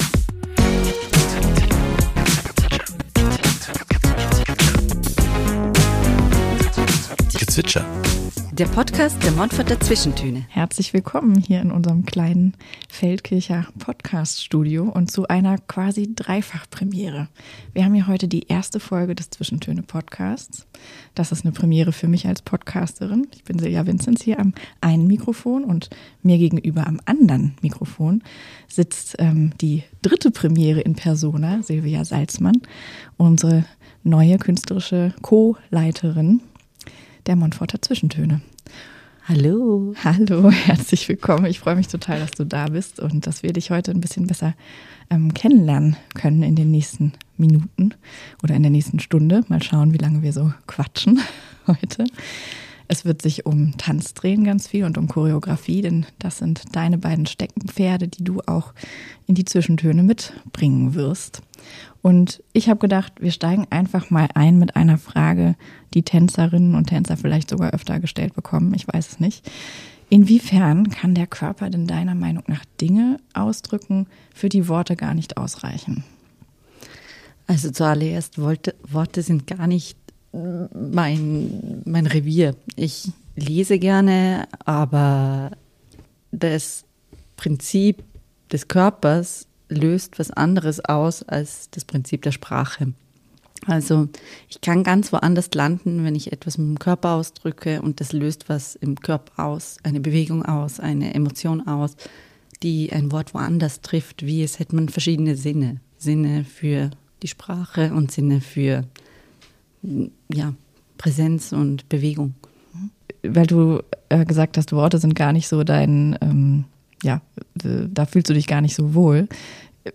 Ein Gespräch über Mut, neue Wege, Bühne und Publikum sowie Zukunftsvisionen.